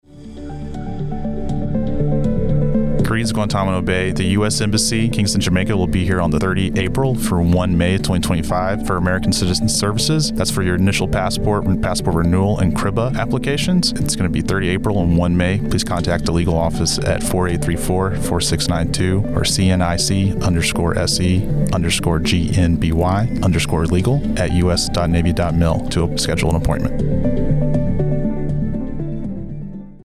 A radio spot informing the residents of Naval Station...